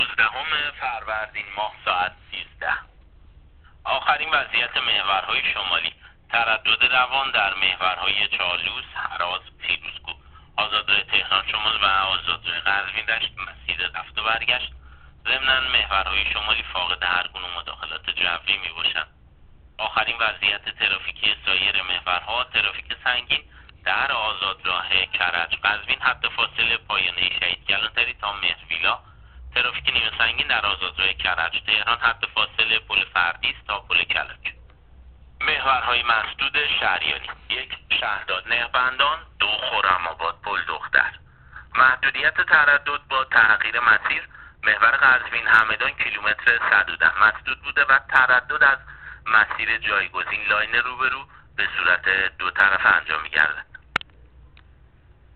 گزارش رادیو اینترنتی از آخرین وضعیت ترافیکی جاده‌ها تا ساعت ۱۳ نوزدهم فروردین